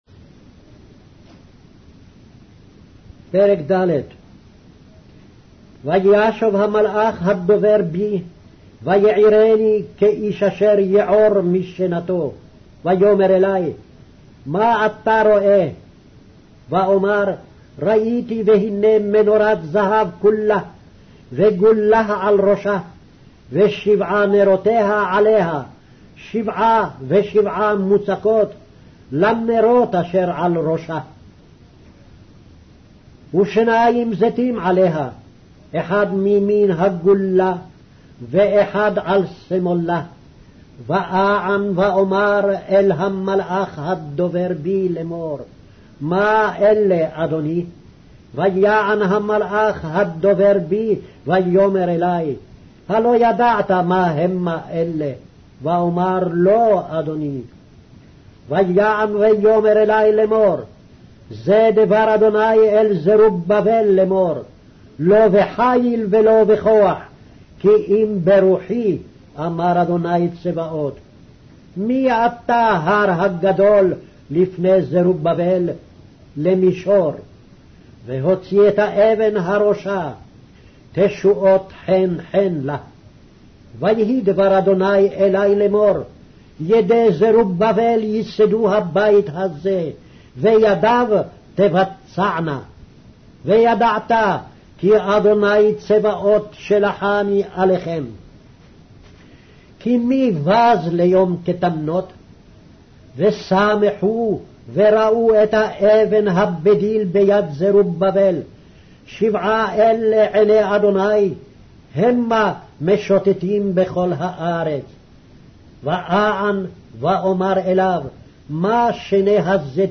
Hebrew Audio Bible - Zechariah 6 in Ervbn bible version